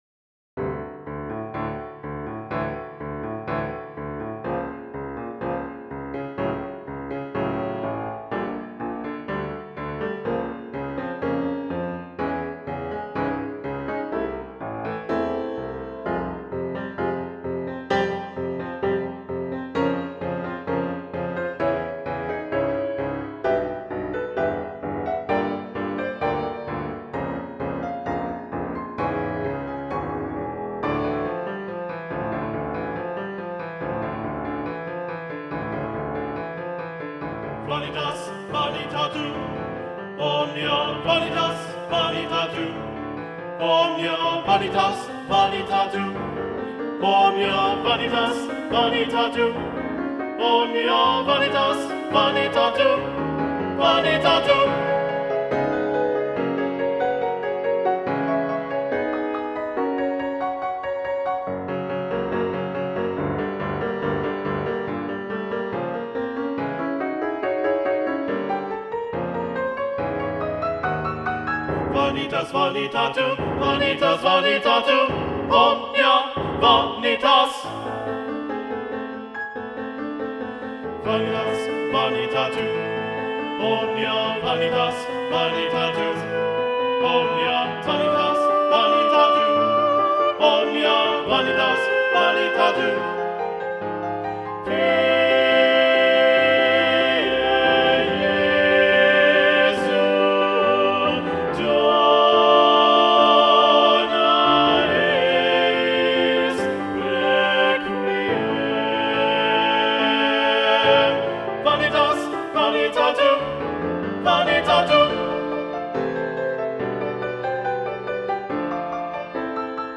Video Only: Vanitas Vanitatum - Balanced Voices